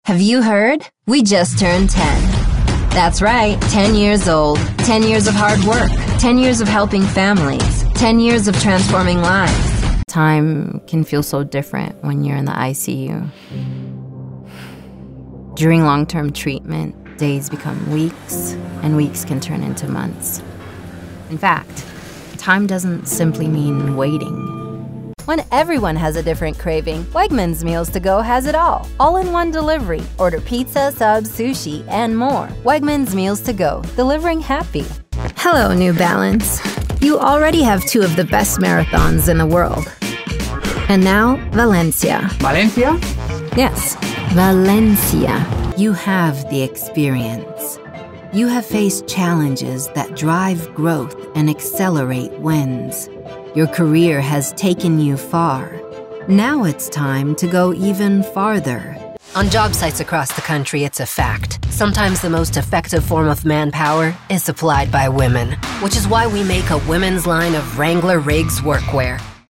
Bilingual and multi-accented artist professional female voice artist
Accents and Dialects
Latin, Brazilian, French, Caribbean, German
Voice Age
Teen
Young Adult
Commercial